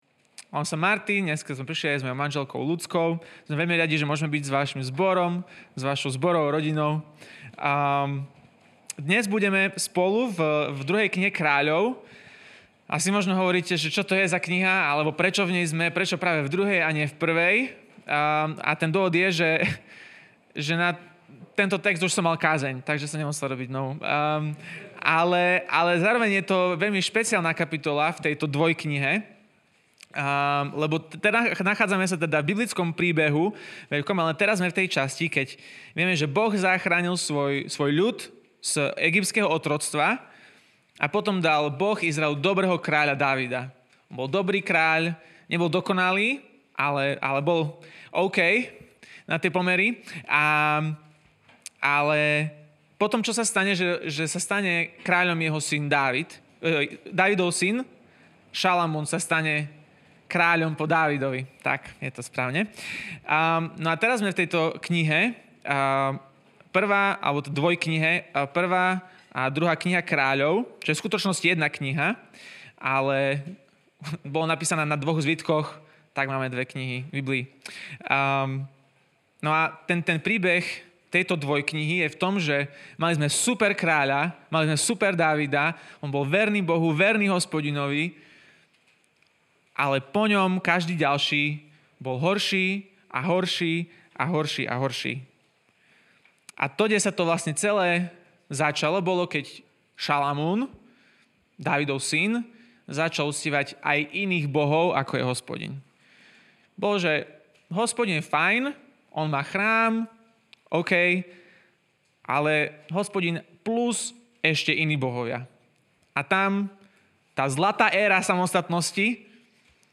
Odpuštění Samostatná kázání 2.
Inclusive-God-sermon.mp3